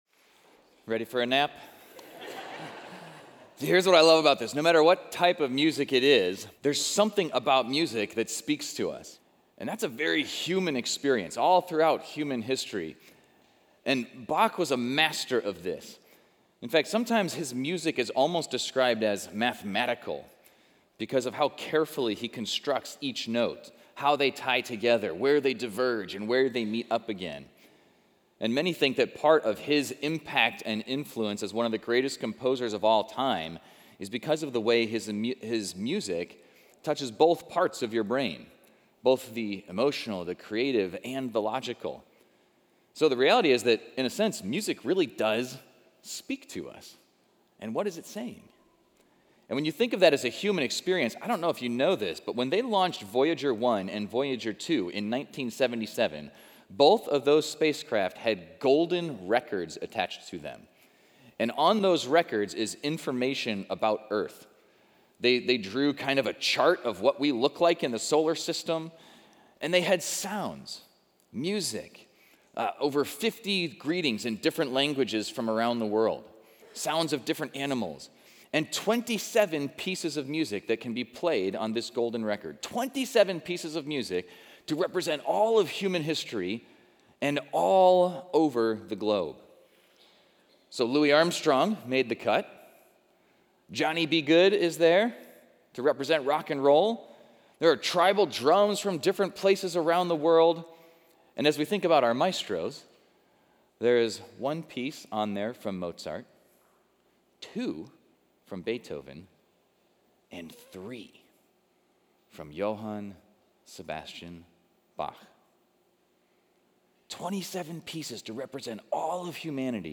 Exploring Service / Maestros / Bach's Joyful Uplift